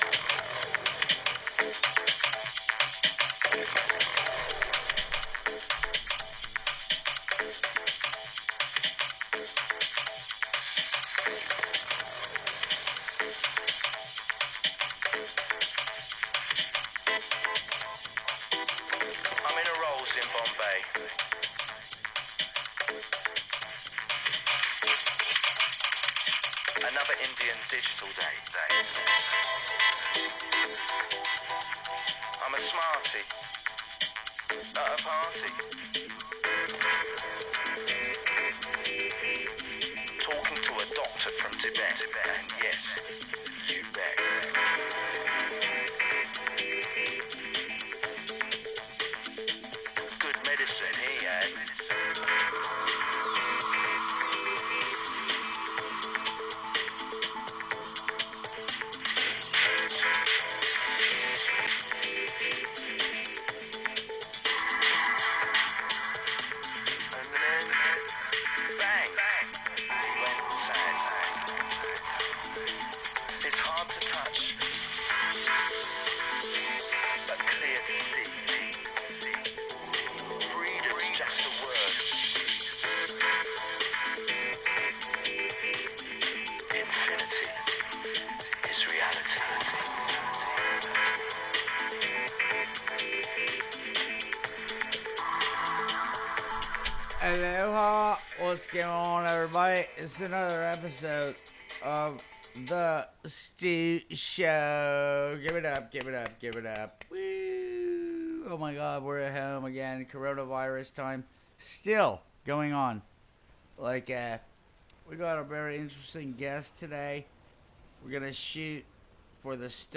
Still at home staying safe. We have 2 guests on today's show.